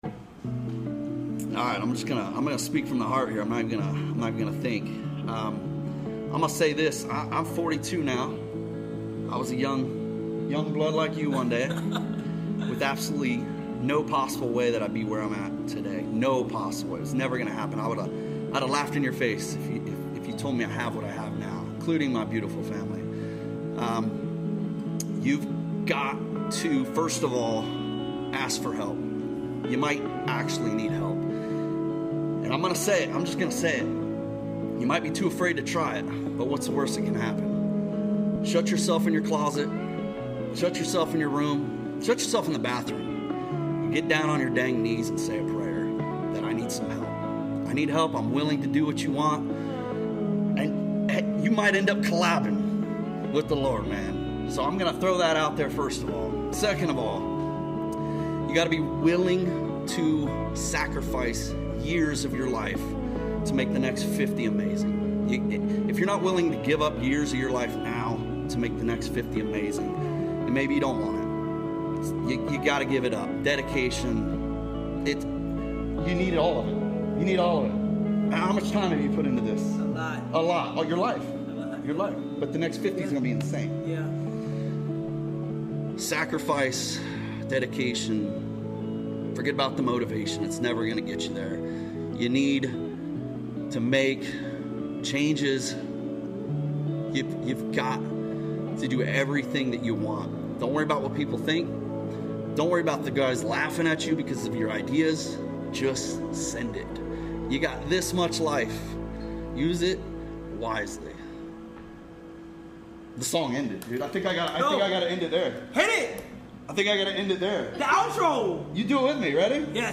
motivational speech